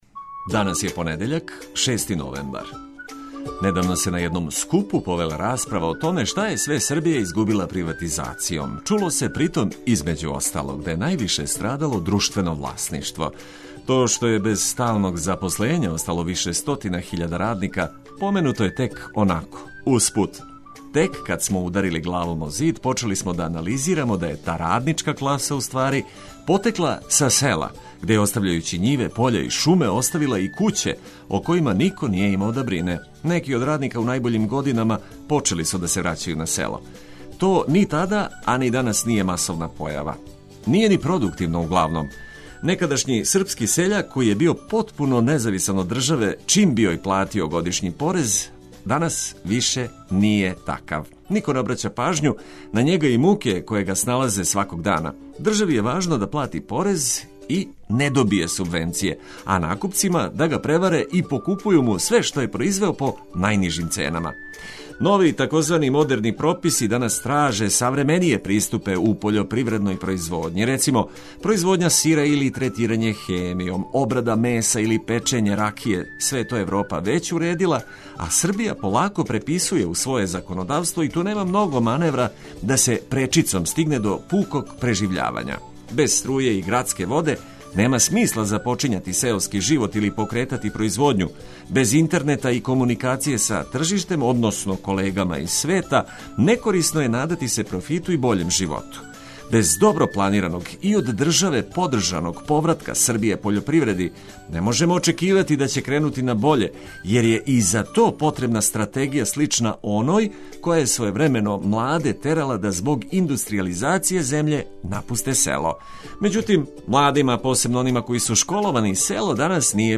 Сјајно расположење, корисне приче и величанствени хитови већ су припремљени.